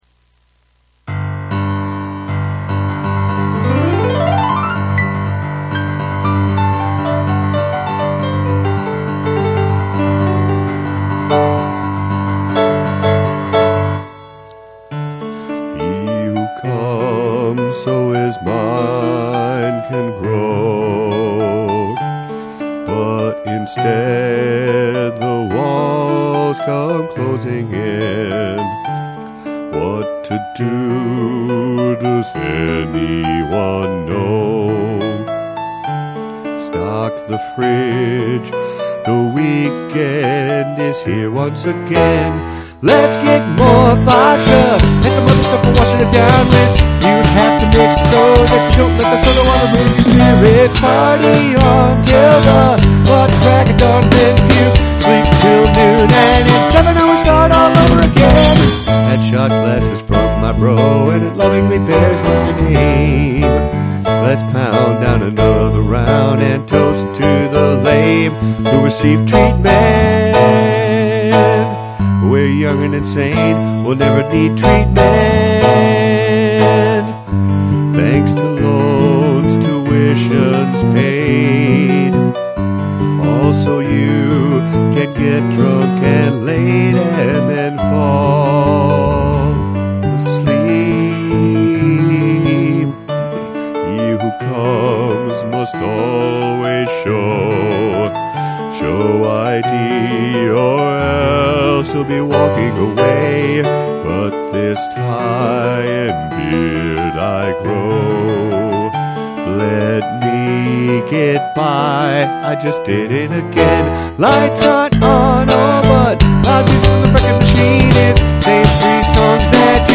Key of D  –  June, 1999